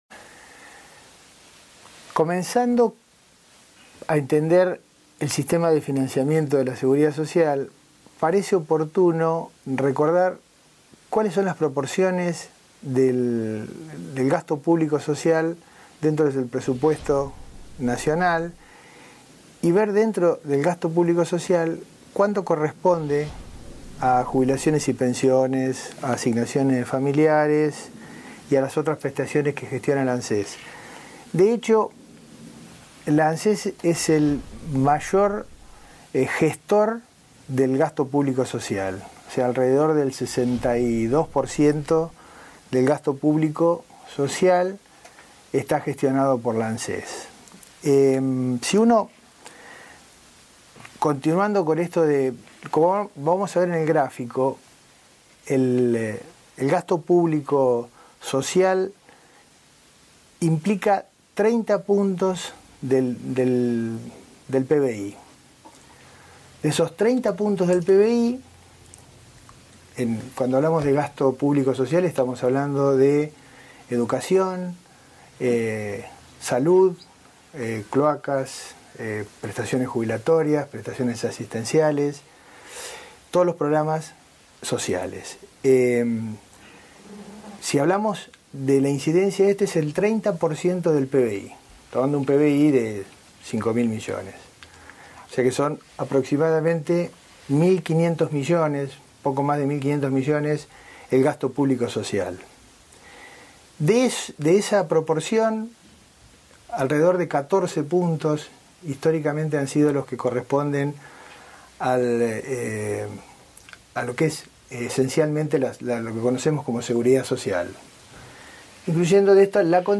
Curso: La seguridad social